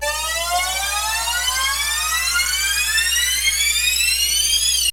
MB Trans FX (6).wav